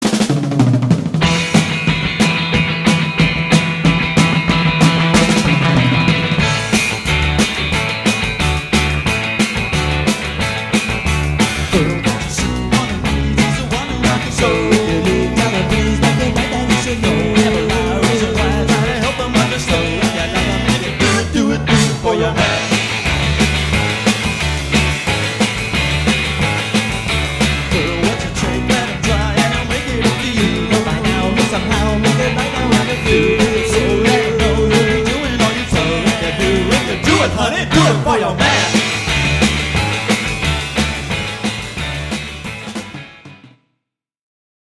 voc/gtr
voc/bass
drums.